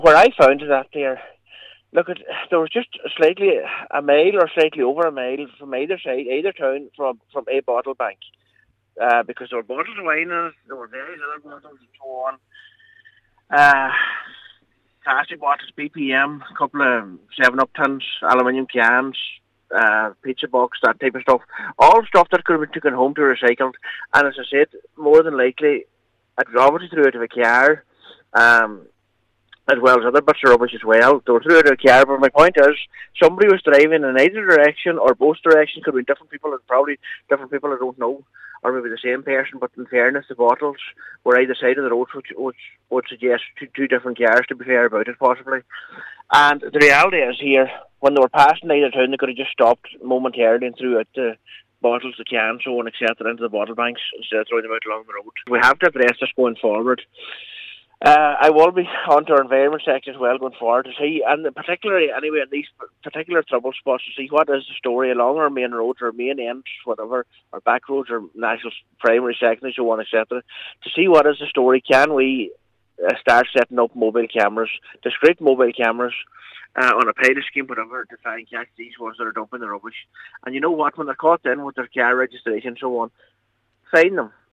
Cllr Michael McClafferty says a possible solution to the issue of littering could be a pilot scheme for mobile cameras to detect people littering on public roads: